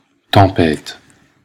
ÄäntäminenBelgique (Brabant wallon):
• IPA: [ˈtɒ̃.pe̞ːt]